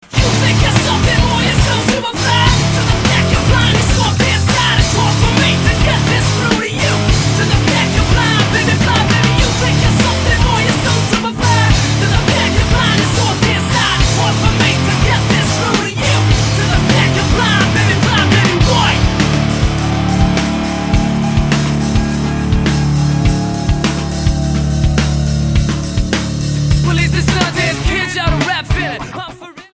STYLE: Pop
Fine singer, fine band, fine songs.
melodic pop/rock